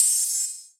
DDK1 OPEN HAT 4.wav